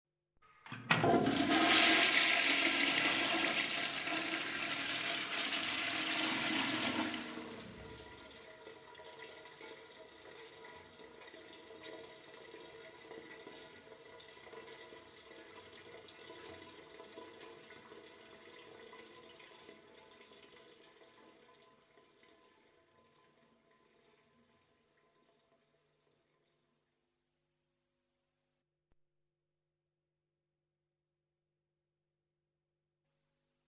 INODORO EFECTO INODORO
Ambient sound effects
inodoro_EFECTO_INODORO.mp3